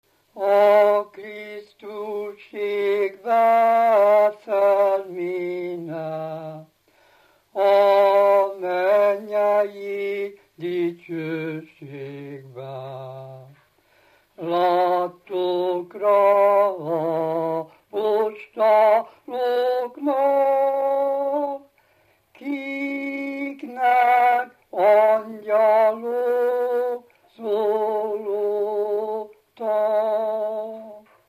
Moldva és Bukovina - Bukovina - Józseffalva
Műfaj: Népének
Stílus: 2. Ereszkedő dúr dallamok